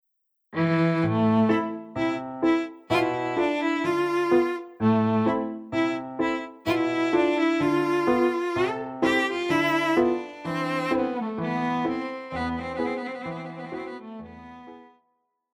Classical
Cello
Piano
Instrumental
Solo with accompaniment